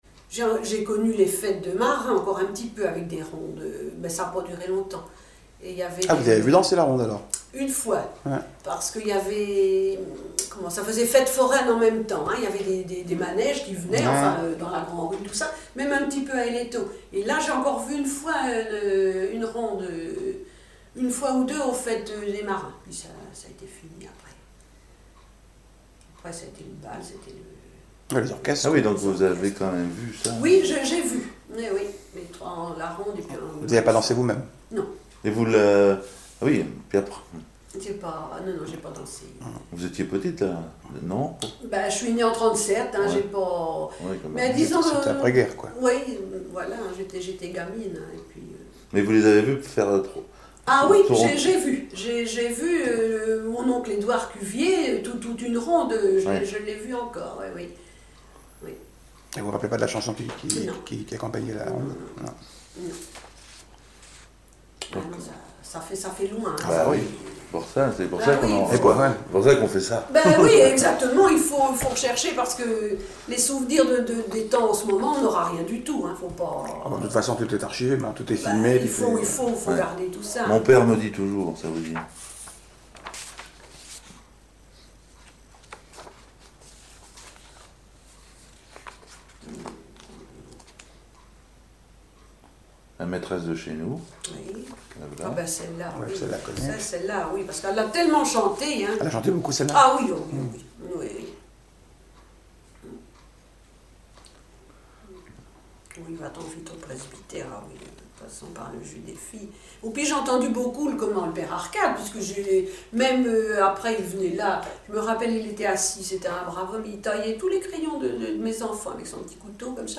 Chansons et commentaires
Témoignage